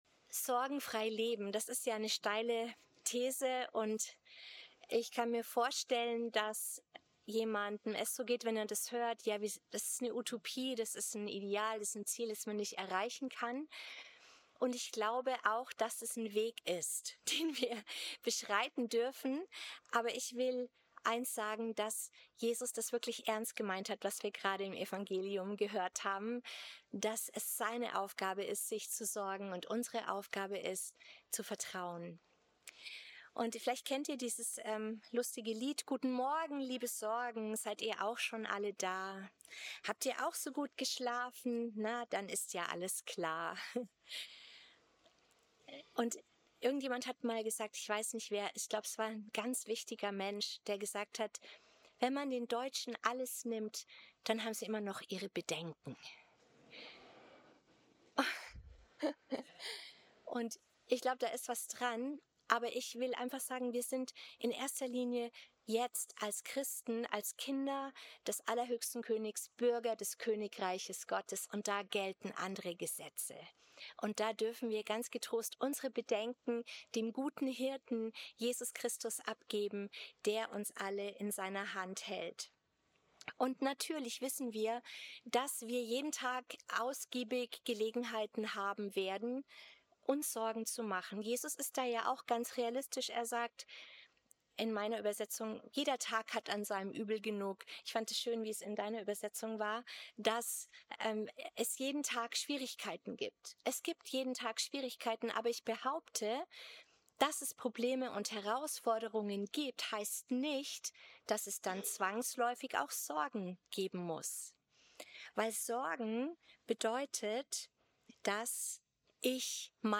Predigt im Christus-Pavillon Volkenroda am Pfingstsonntag